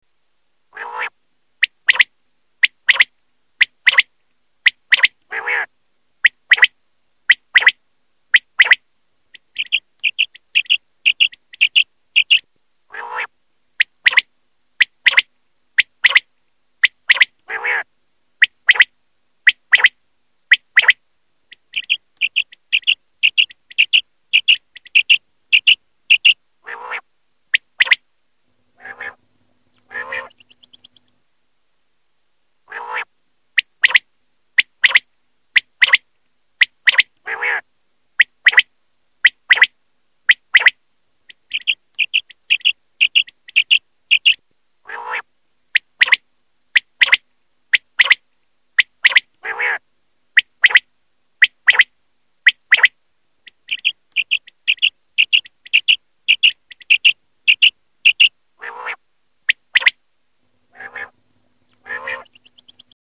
prepelita.mp3